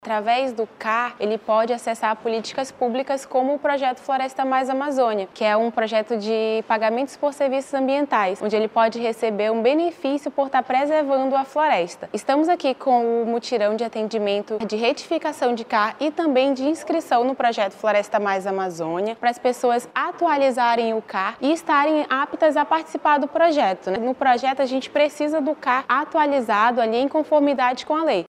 SONORA-1-CADASTRO-AGRICULTORES-.mp3